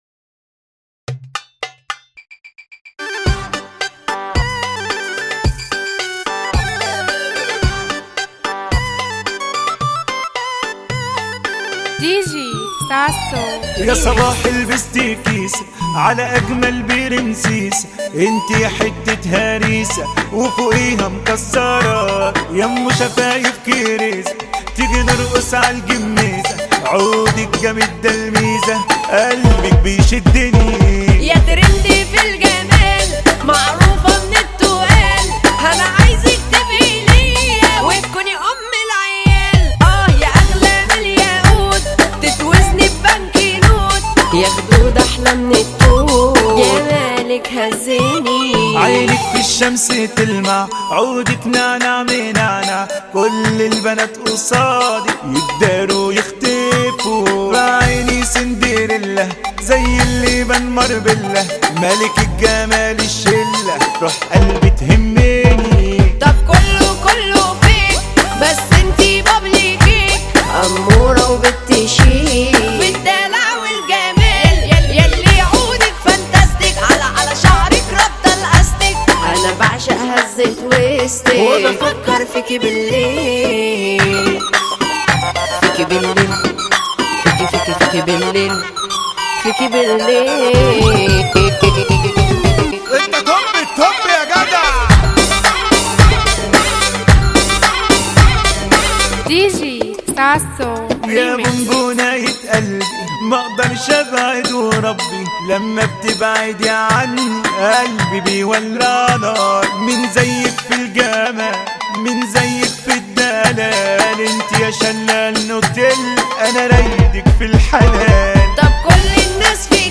مهرجانات جديدة